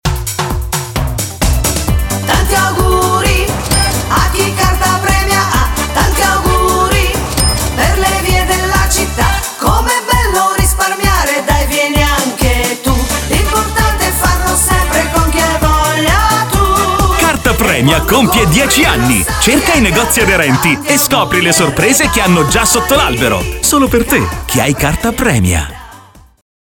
Spot Anniversario